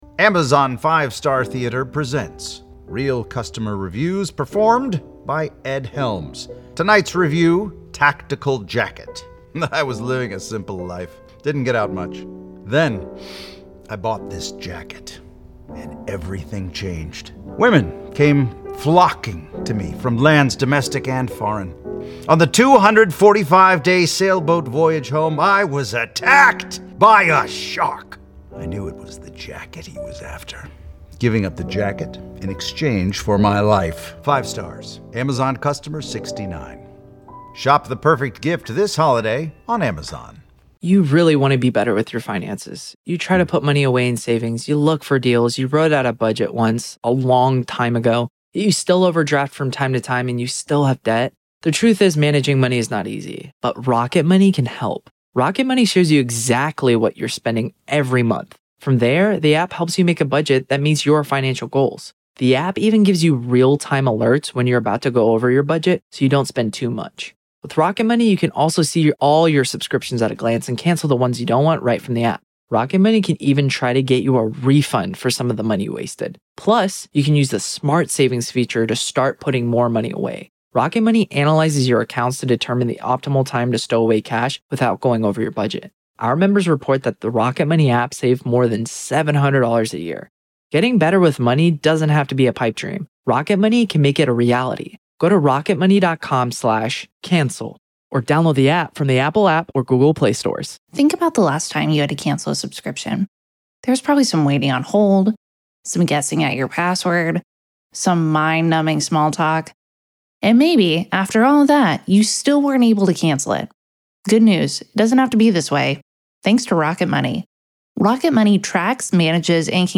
Telles Takes the Stand-Raw Court Audio-NEVADA v. Robert Telles DAY 7 Part 3